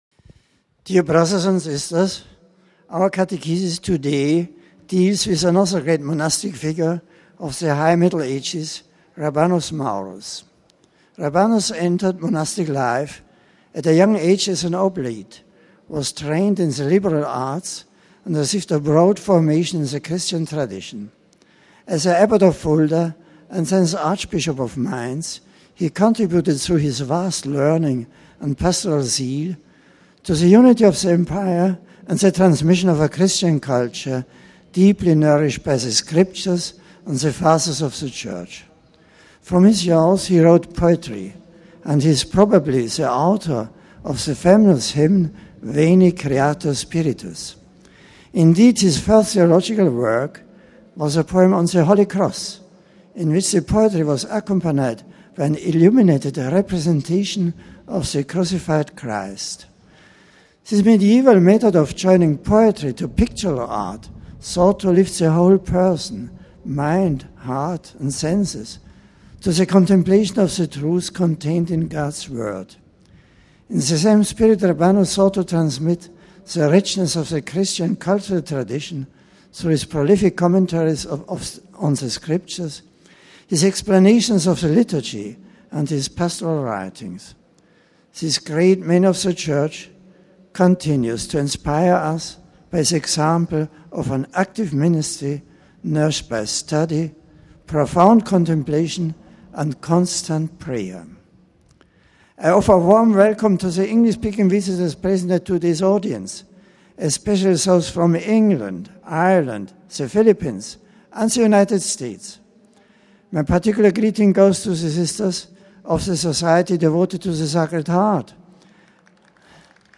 The General Audience of June 3rd was held in the open in St. Peter’s Square. An aide greeted the Pope on behalf of the English-speaking pilgrims, presenting the various groups to him. Pope Benedict XVI then delivered a reflection in English: